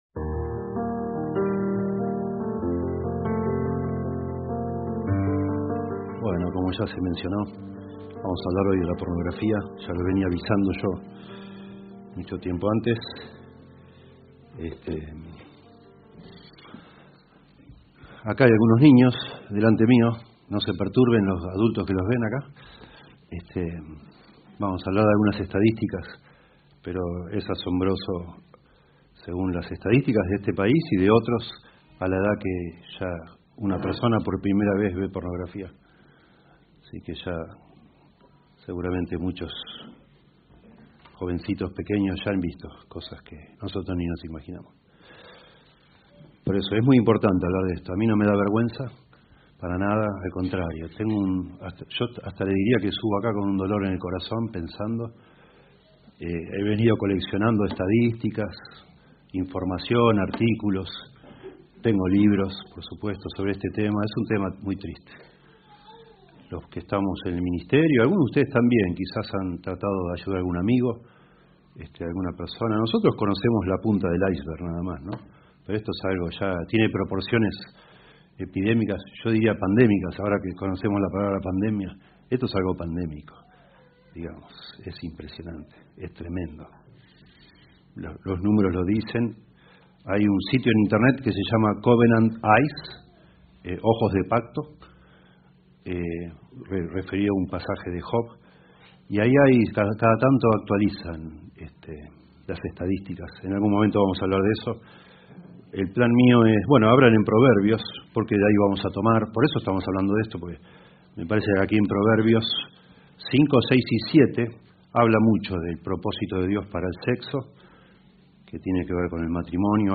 Reina-Valera 1960 (RVR1960) Video del Sermón Audio del Sermón Descargar audio Temas: